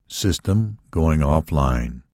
描述：言语系统主题
声道立体声